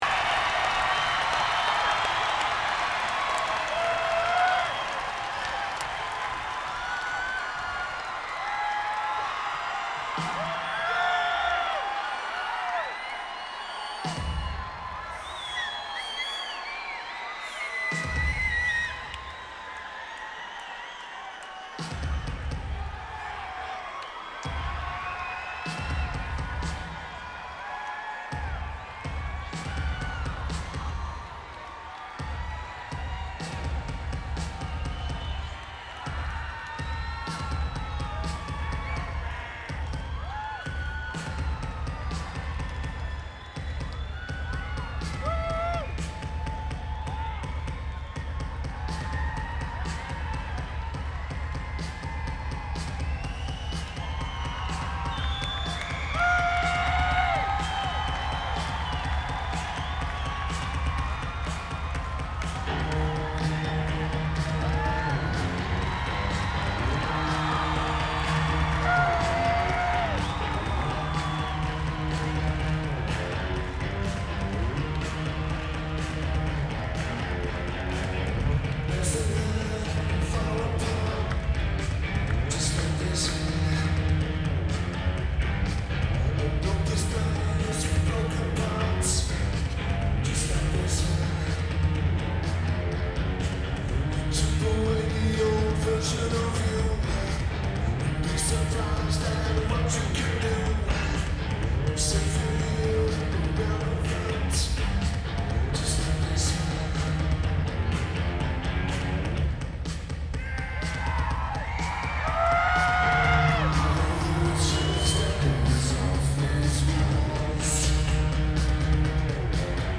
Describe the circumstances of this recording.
Mohegan Sun Arena Lineage: Audio - AUD (CSB's + BB + Microtrack II)